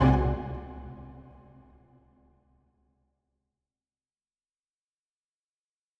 Strings Hit 2 Pizzicato
Bring new life to your videos with professional orchestral sounds.
Strings-Hit-2-Pizzicato.wav